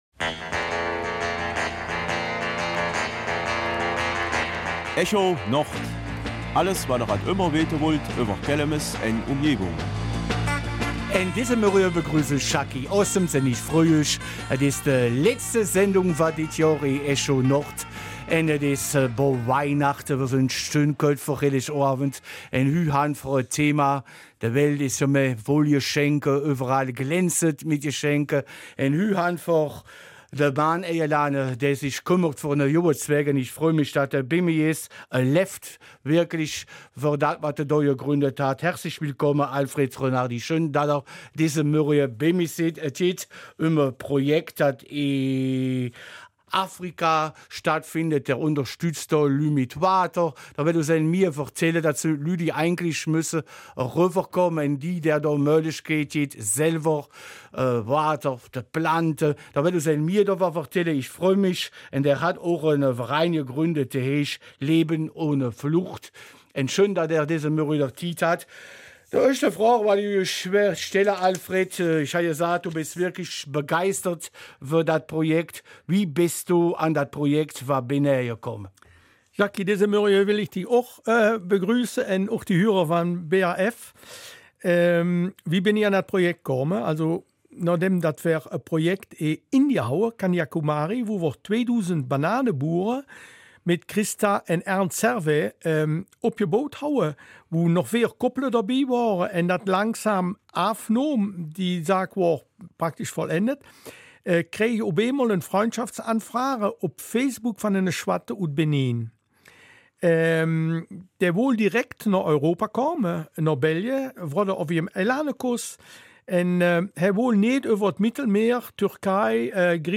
Kelmiser Mundart - 17. Dezember